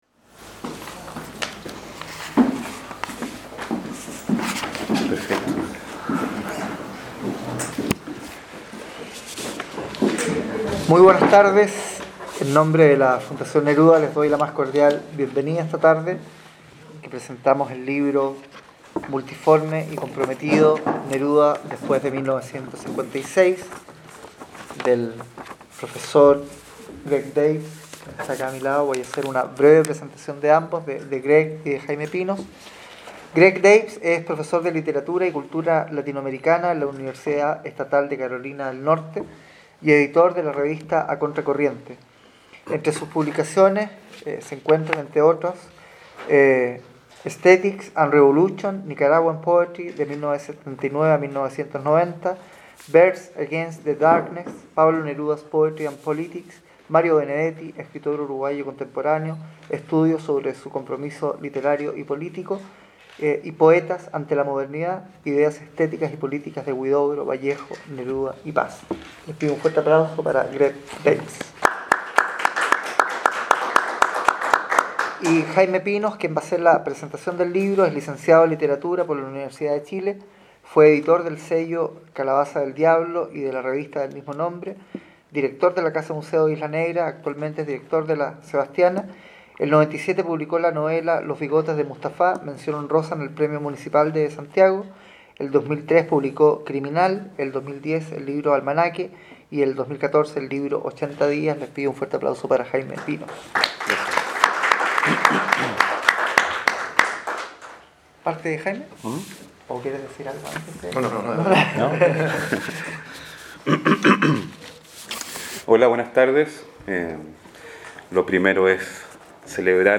Neruda después de 1956, en la sala del Centro Cultural La Sebastiana.